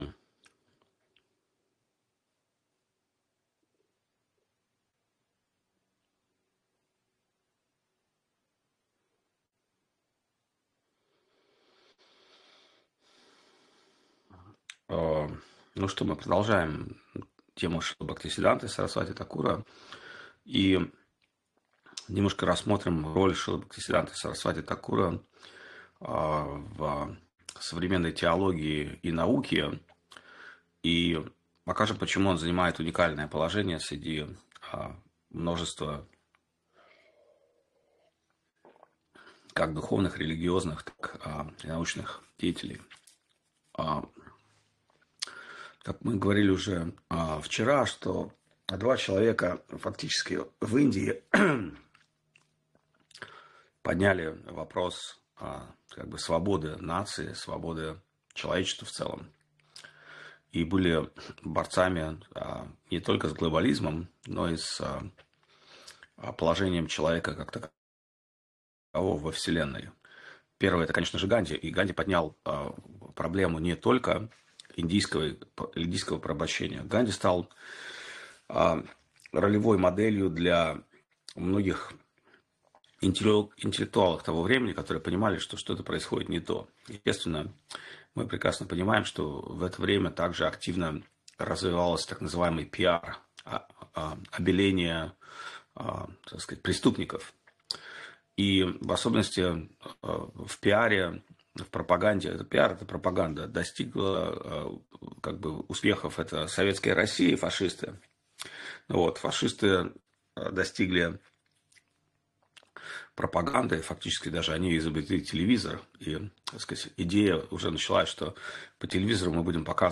Место: США
Лекции полностью
Проповедь